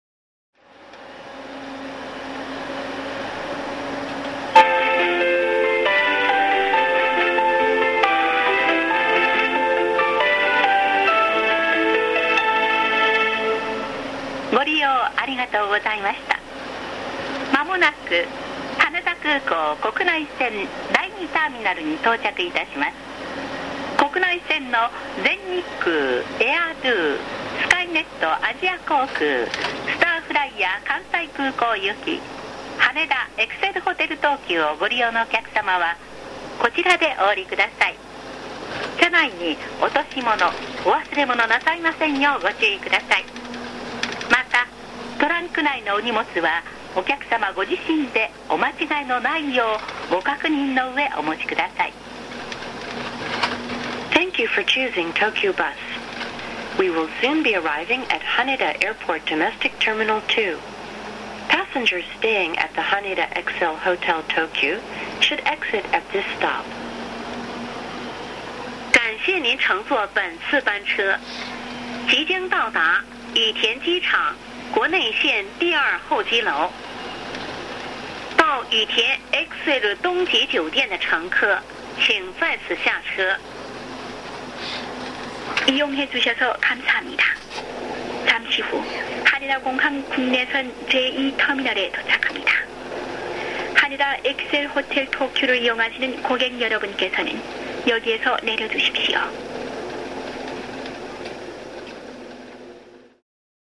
車内アナウンスコレクション
このページでは主に私がよく利用する東急バスと、メロディーチャイムが魅力の国際興業バスの車内放送を公開します。